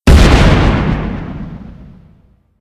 增加冰车爆炸音效
zomboniExplosion.ogg